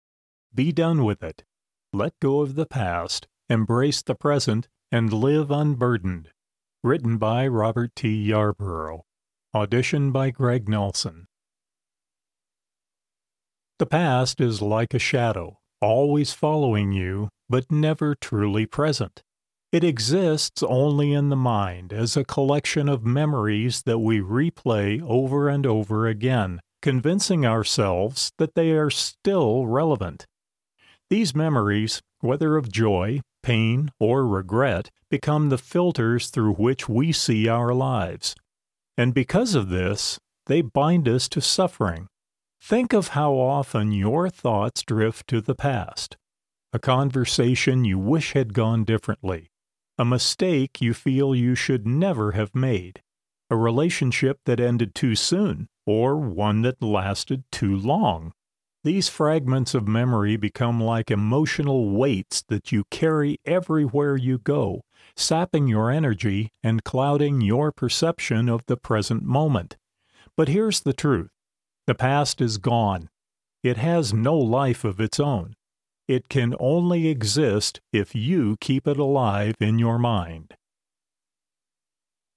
Audiobook narration
Middle Aged
Audition for Be Done With It.mp3